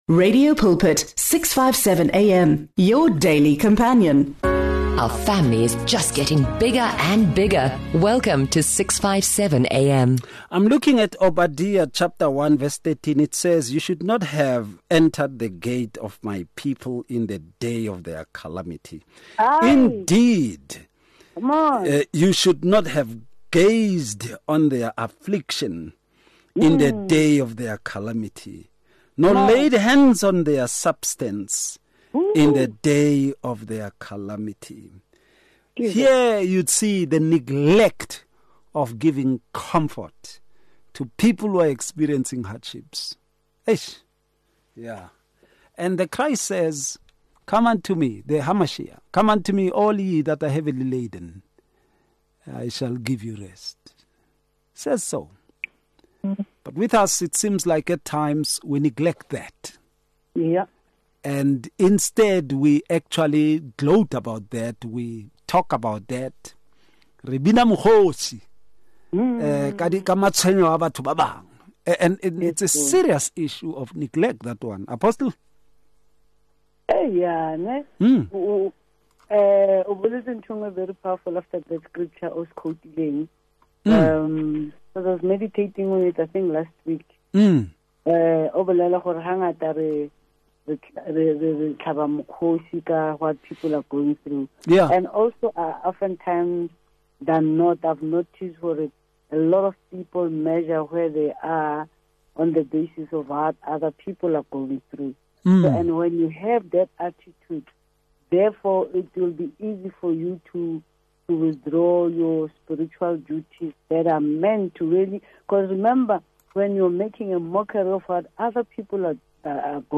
The discussion highlights how neglect in these areas weakens both individual faith and the broader church community. They emphasize the need for intentional commitment to practicing mercy, faithfully carrying out ministry tasks, and actively serving to maintain spiritual health and growth.